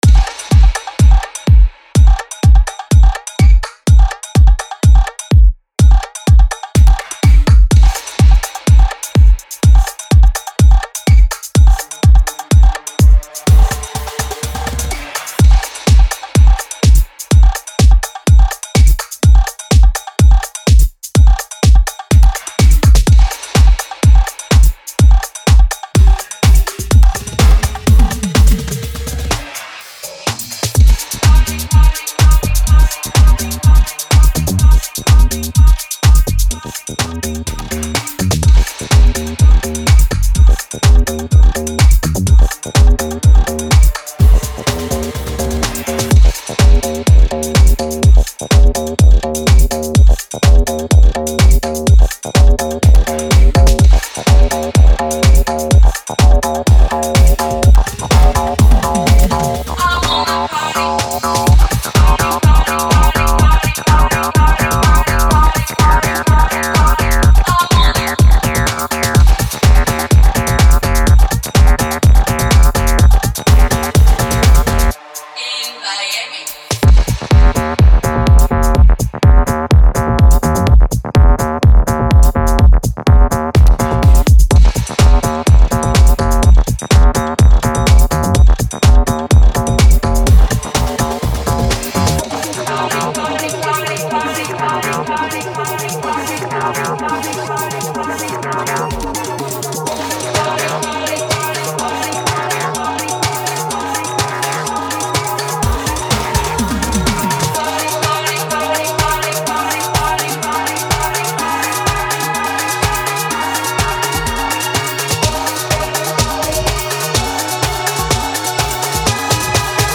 fresh tech-house release